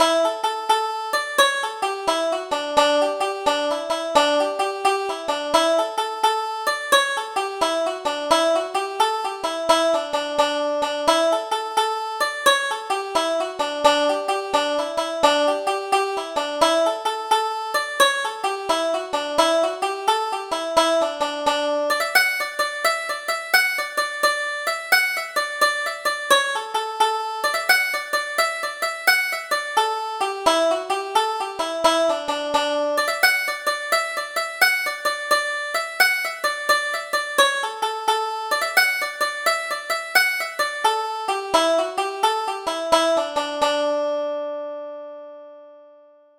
Double Jig: Connie the Soldier